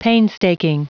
Prononciation du mot painstaking en anglais (fichier audio)
Prononciation du mot : painstaking